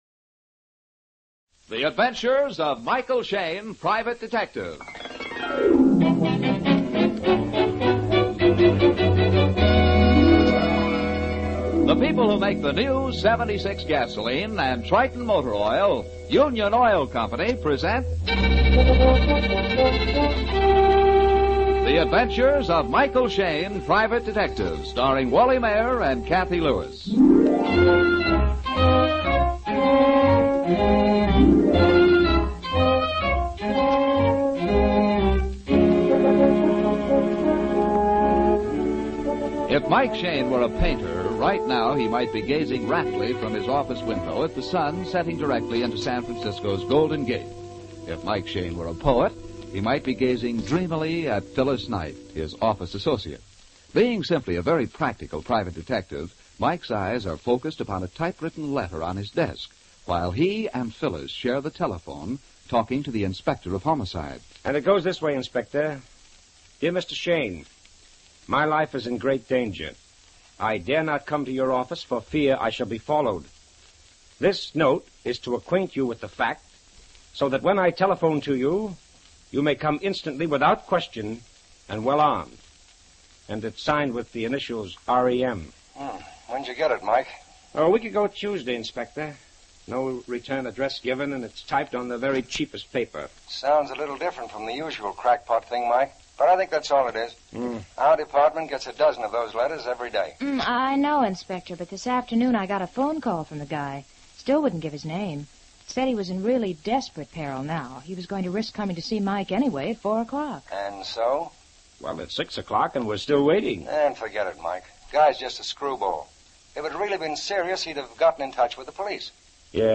Michael Shayne 450903 Signed, R E M, Old Time Radio